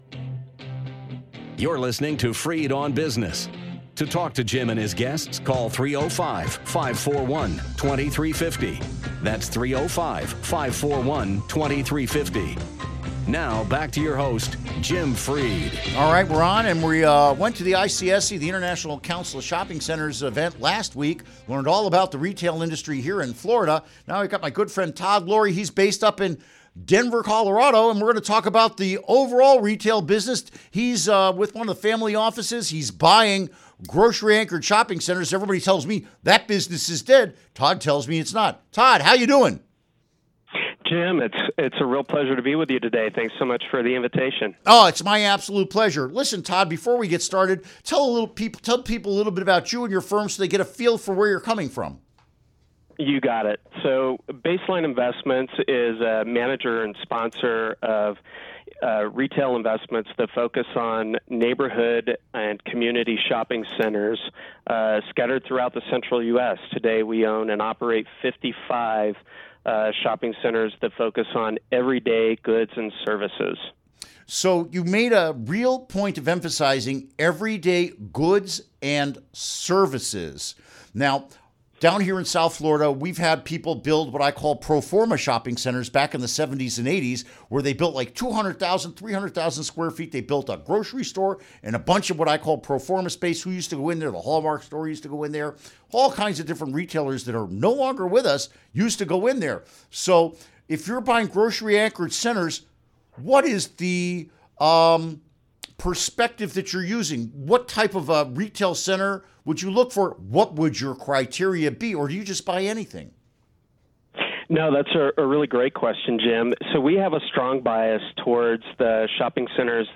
Is retail dead as an investment class? Interview Segment Click here to download Part 1 (To download, right-click and select “Save Link As”.)